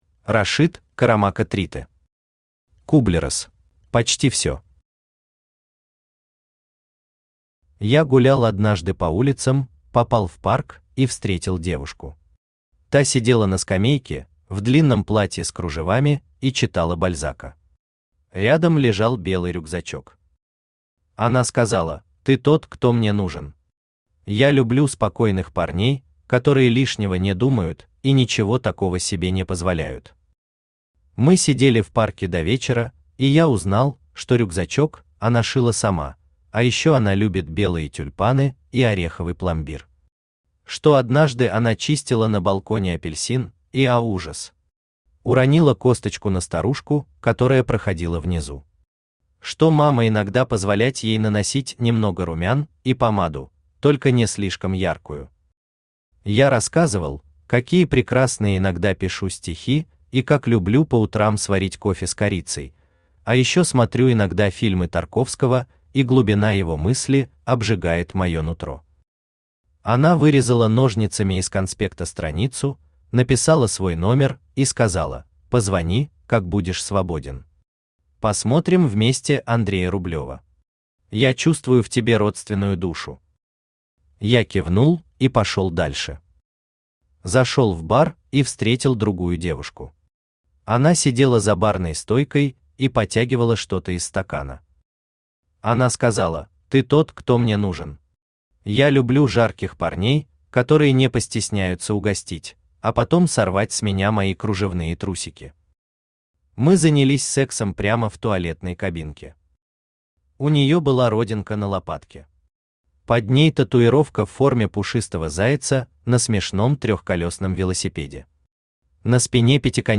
Аудиокнига Кублерос | Библиотека аудиокниг
Aудиокнига Кублерос Автор Рашид Карамако Тритэ Читает аудиокнигу Авточтец ЛитРес.